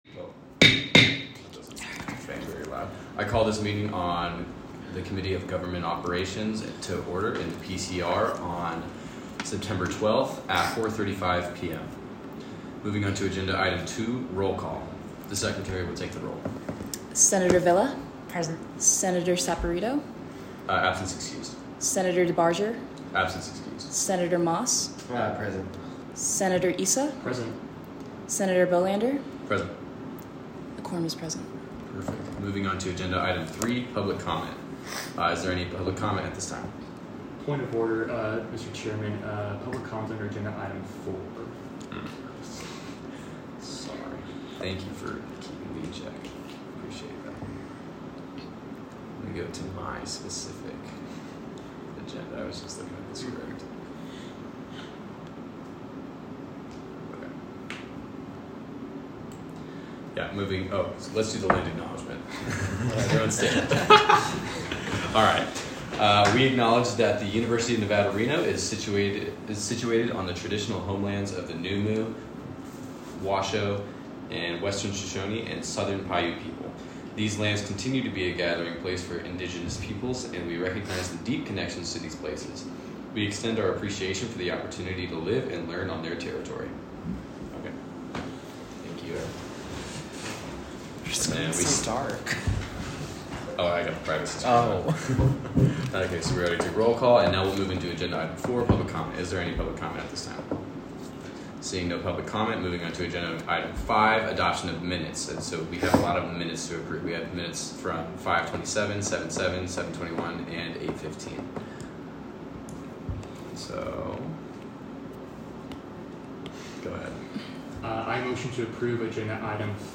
Audio Minutes